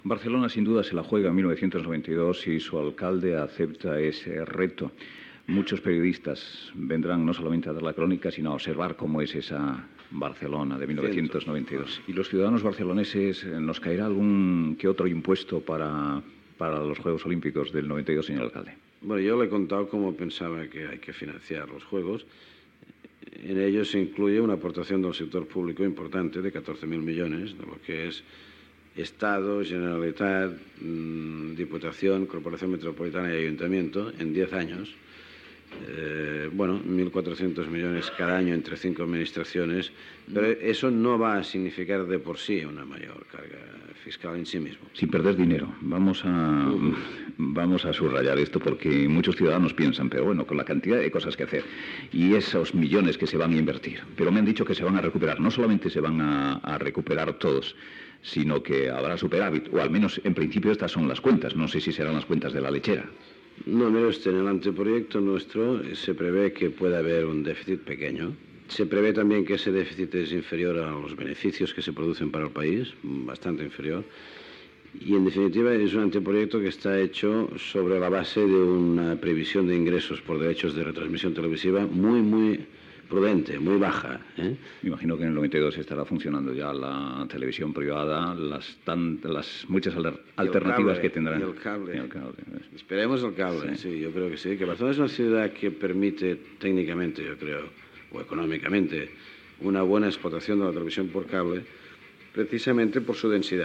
Fragment d'una entrevista a l'alcalde de Barcelona Pasqual Maragall. Com s'han de financiar els Jocs Olímpics de Barcelona 1992.
Info-entreteniment